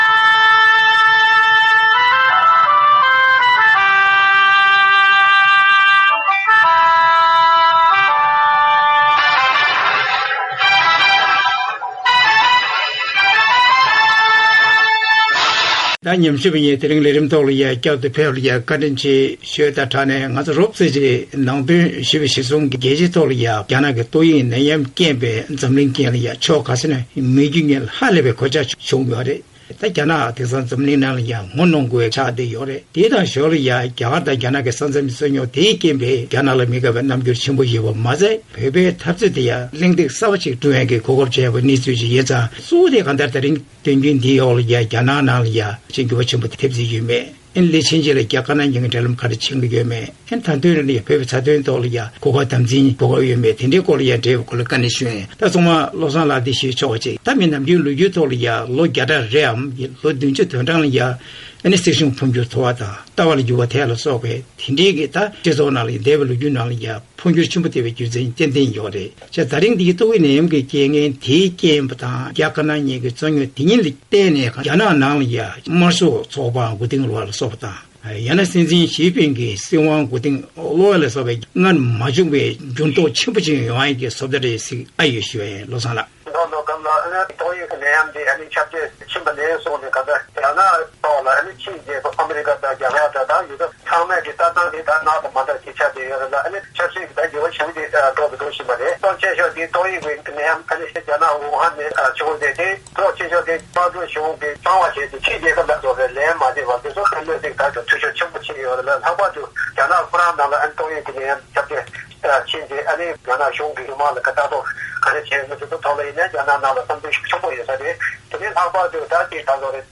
ཨ་མི་རི་དང་རྒྱ་དཀར་ནག་དབར་ཉེན་ཚབས་ཅན་གྱི་འབྲེལ་ལམ་གྱི་ཁ་ཕྱོཌ་སྐོར་གླེང་བ།